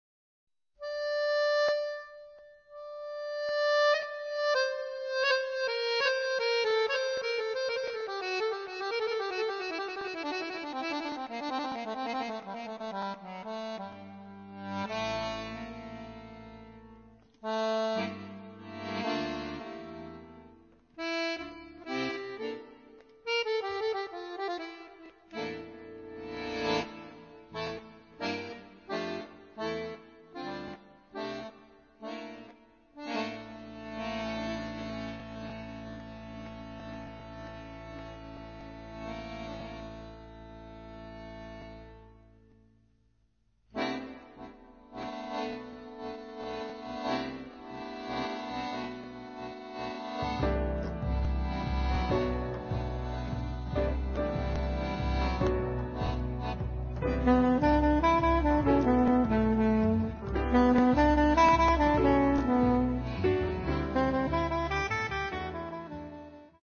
sax
fisarmonica
piano
contrabbasso